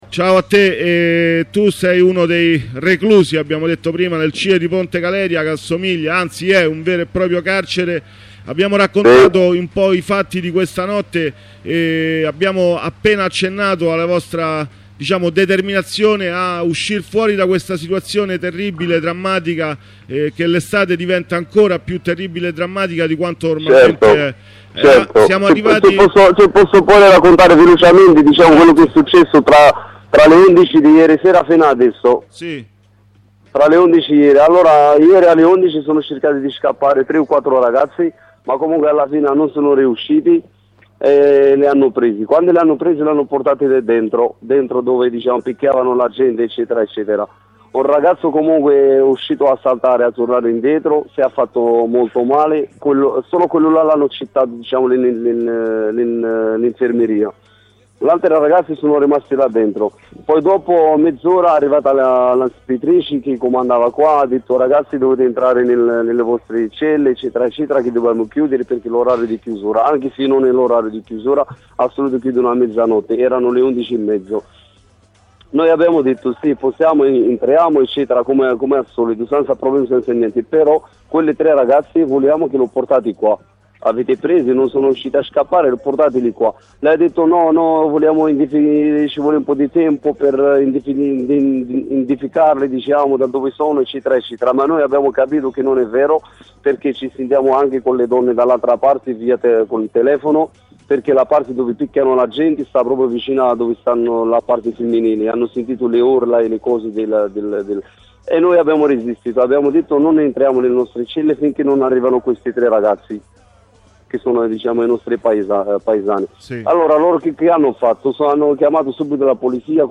Una corrispondenza dal CIe delle ore 16:45